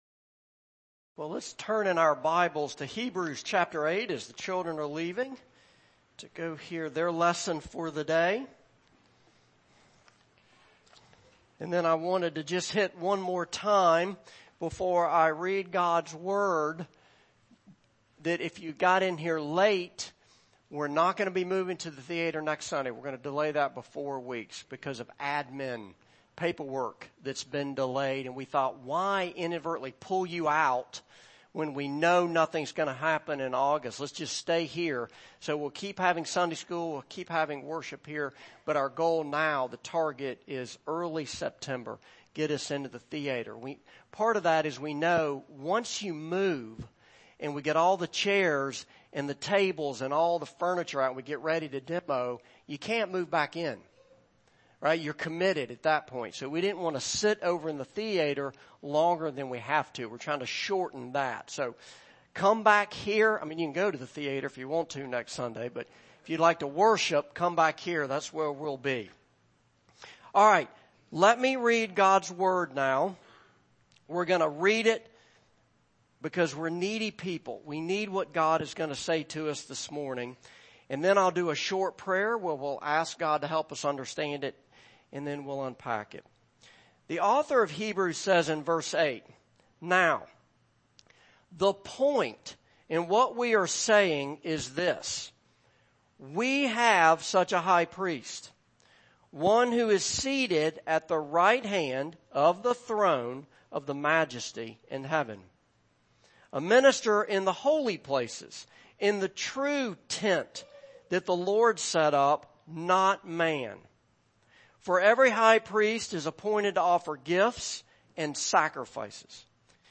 Passage: Hebrews 8:1-7 Service Type: Morning Service